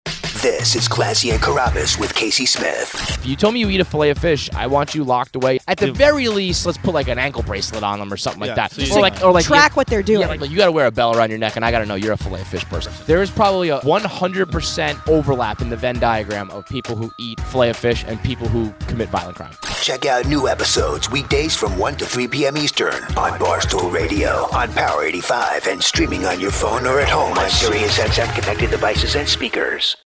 While working at SiriusXM on Barstool Radio, I sometimes put together 30-second spots that are played on other stations to promote the channel.
cck-promo-filet-o-fish.mp3